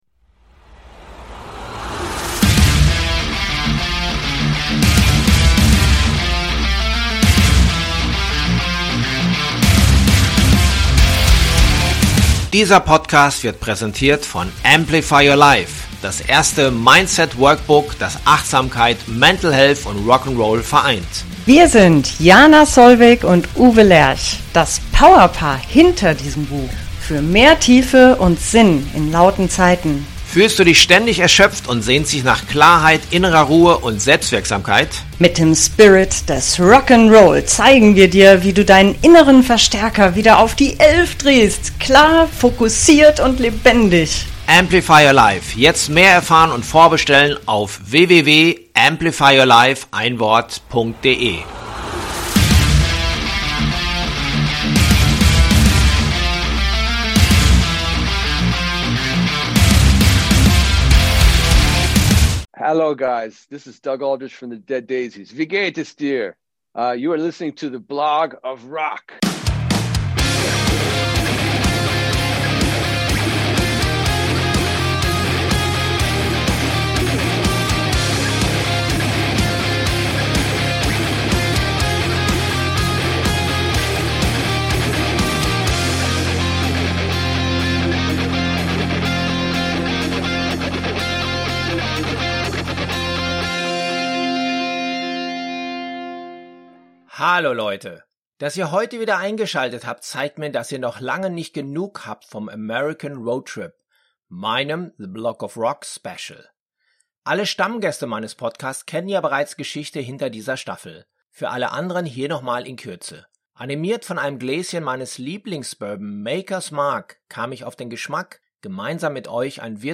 Hier kennt sich unser heutiger Gast bestens aus: Doug Aldrich .